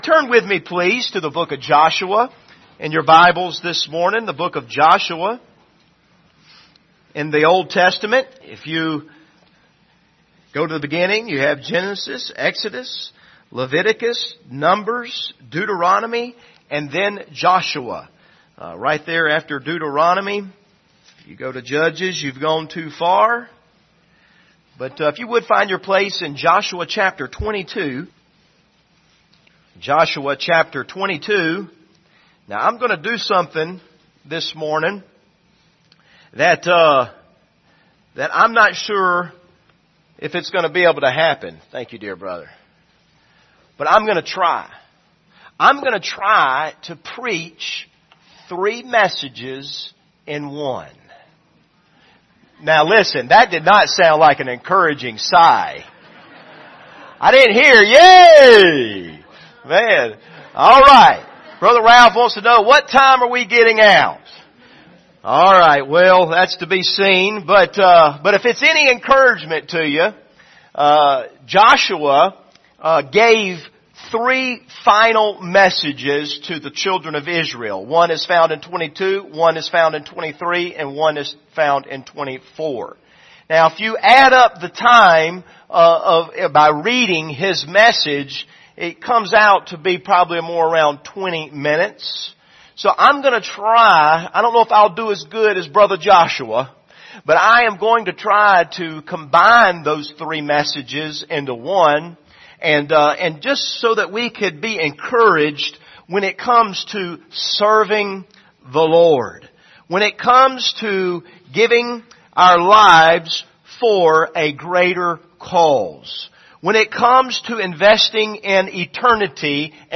Passage: Joshua 22-24 Service Type: Sunday Morning